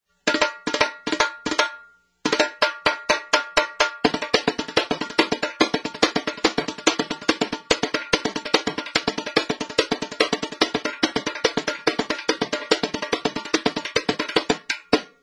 .בעל צליל ייחודי, שמדבר בשפה משלו
repinique.wma